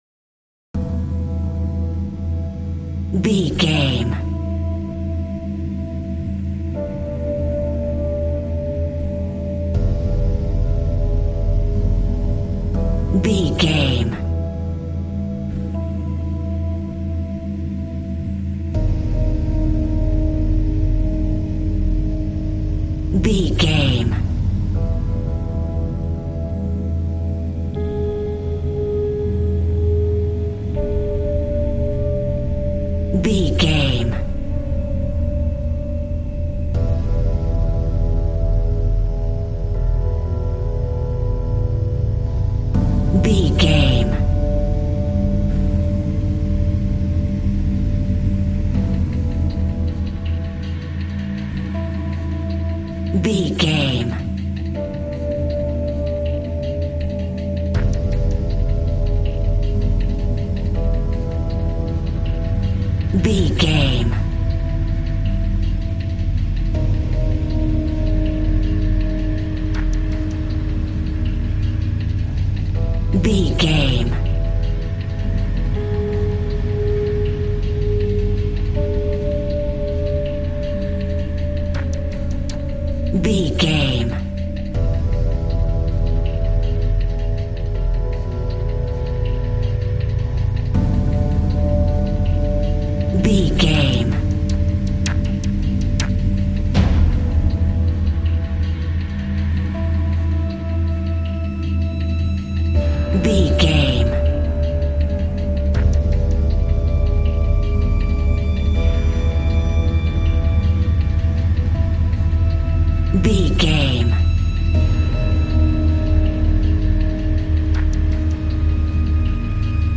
Aeolian/Minor
tension
dark
piano
synthesiser